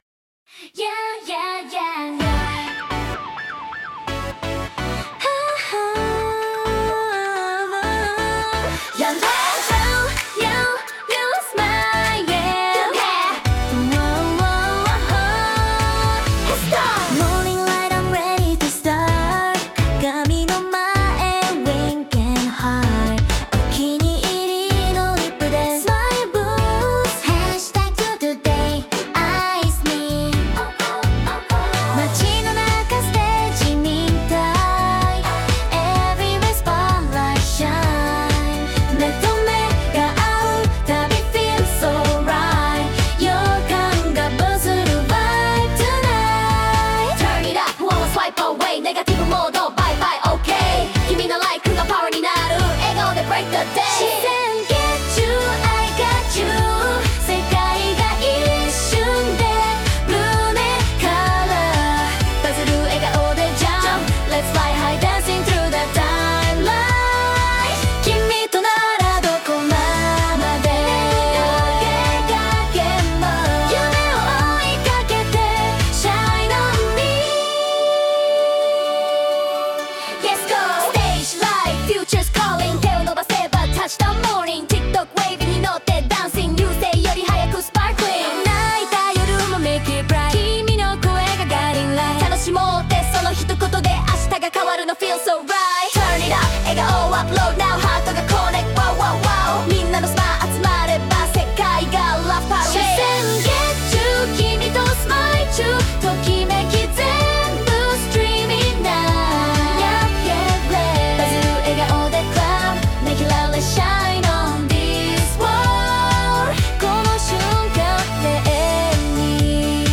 明るい気持ちになれるハッピーソング！